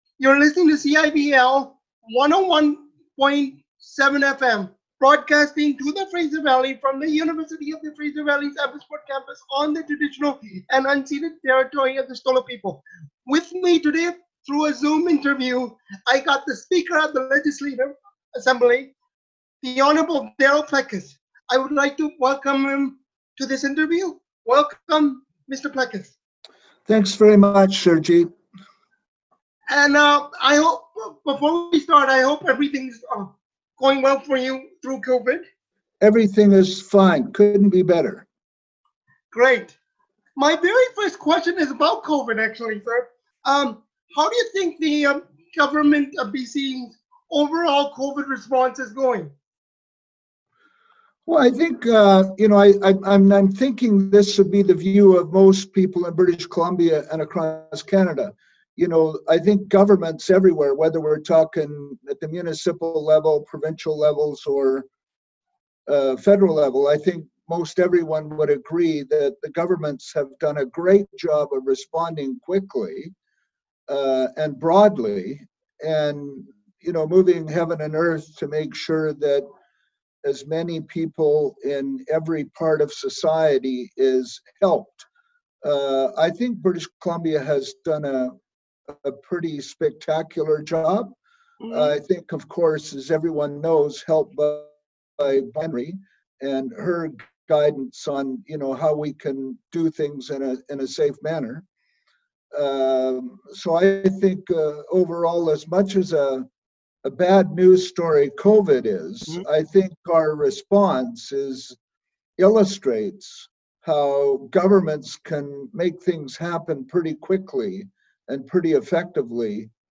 In an interview with CIVL, the outgoing Speaker discusses BC’s COVID-19 response, investigations of the BC legislature and why he chose not to run again as MLA for Abbotsford South.